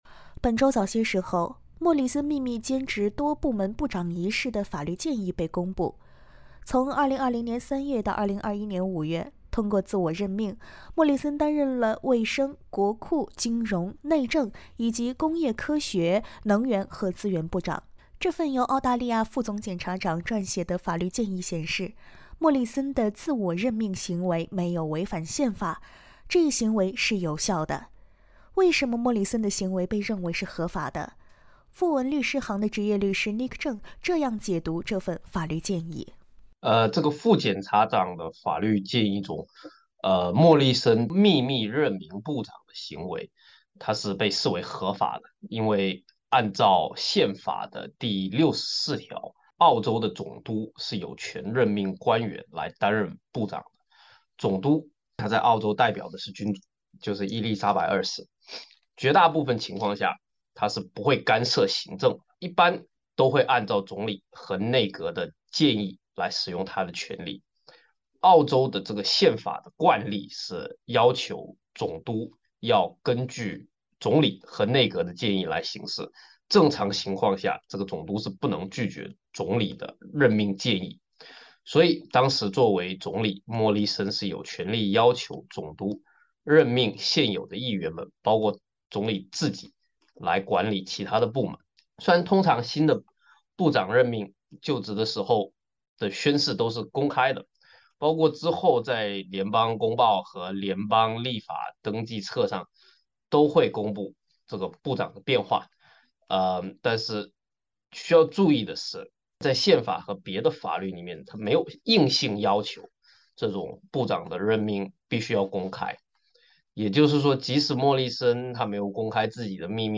请听采访： LISTEN TO 【解读】带你读懂莫里森兼职门的法律报告：前总理的做法为何不违法？